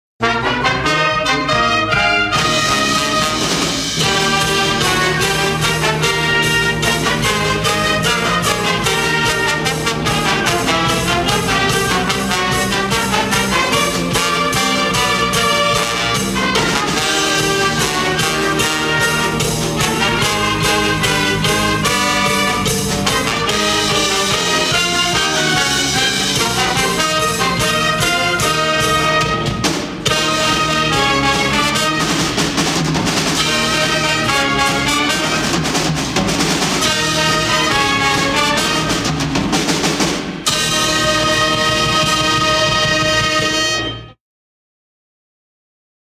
Pep Band - Fight Song.mp3